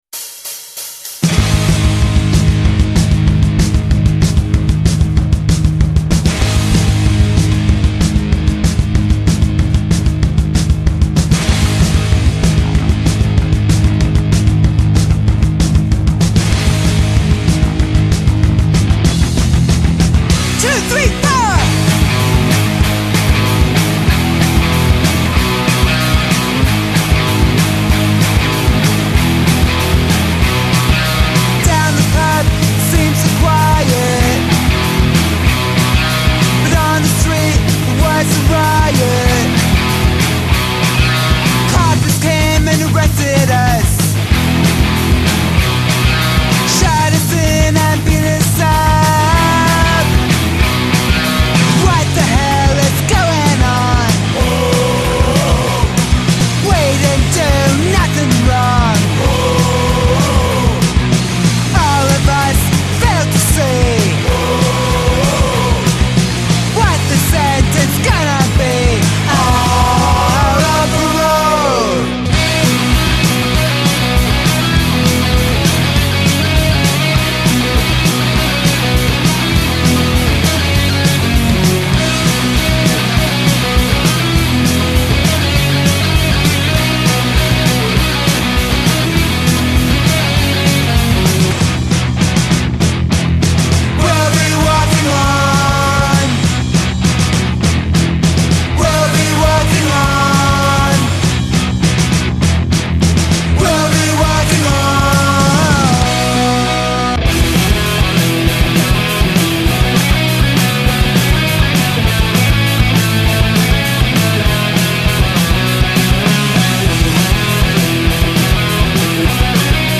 14 melodische Punk/Oi-Hymnen